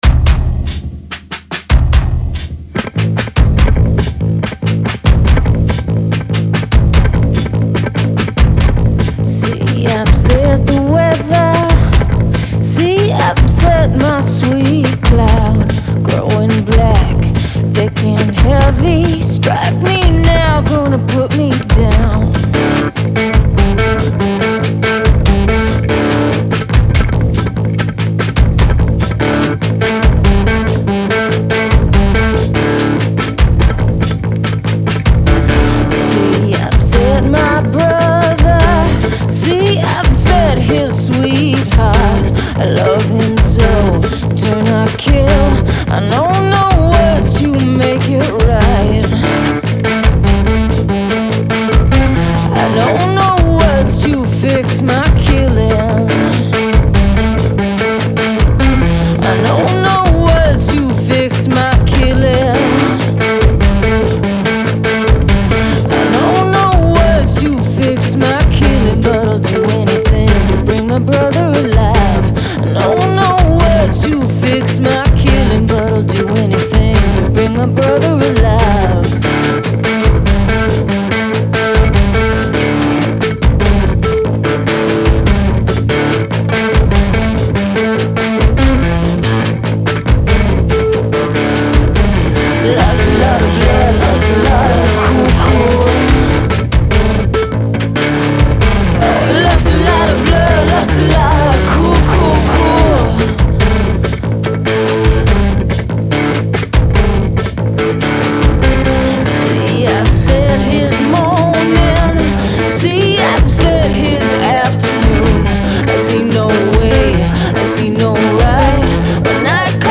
Genre : Pop, Rock, Alternatif et Indé